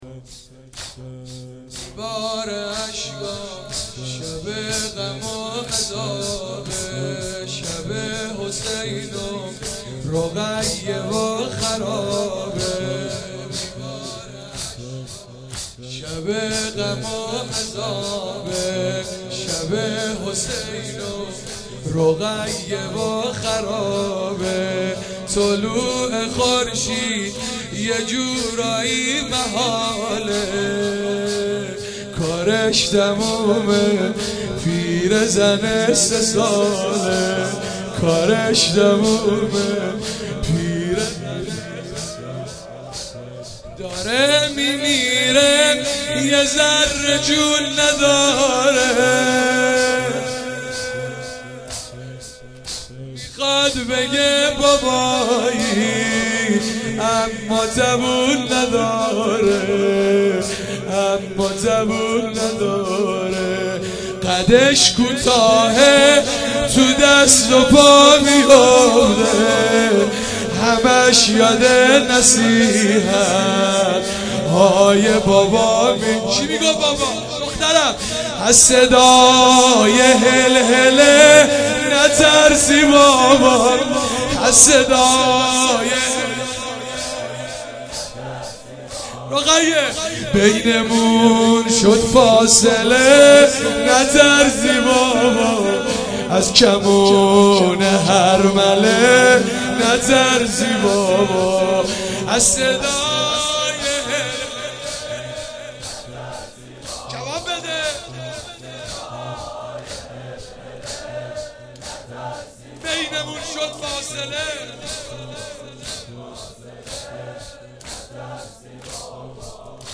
01 heiate alamdar mashhad.mp3